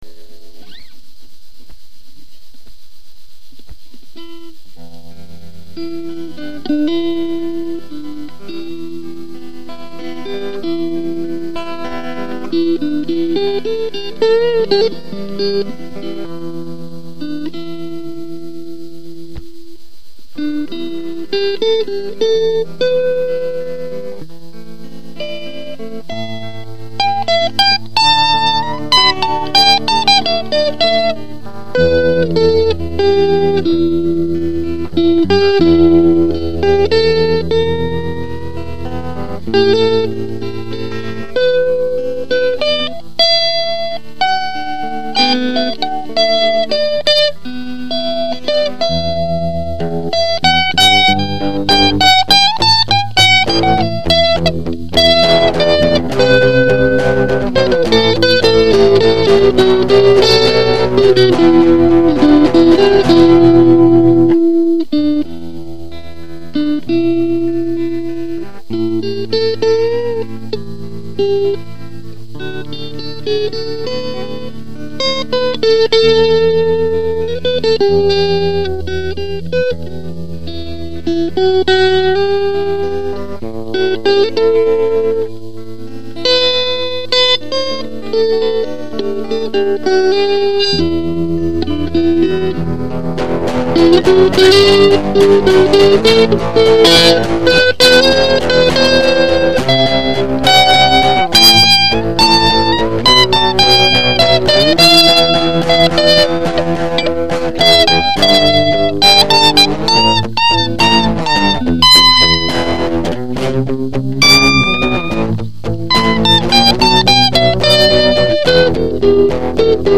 Нечто очень грустное...